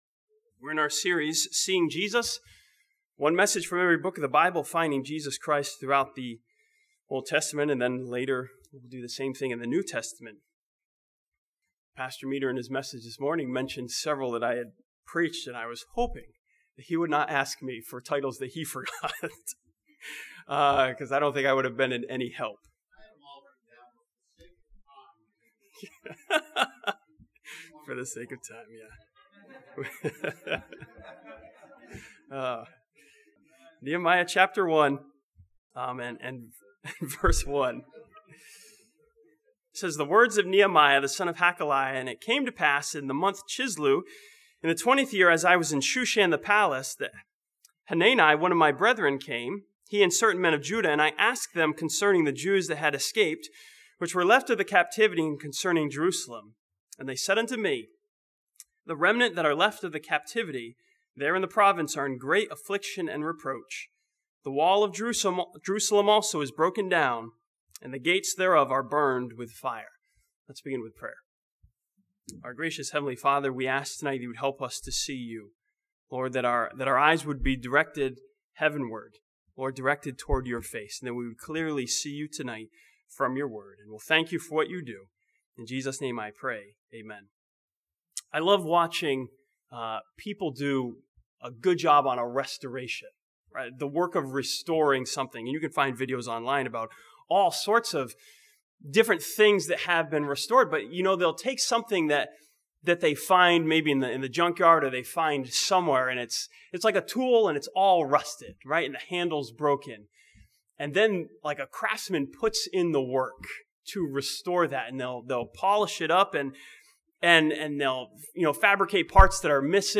This sermon from Nehemiah chapter 13 sees Jesus Christ in Nehemiah as our Restorer who works to restore our hearts.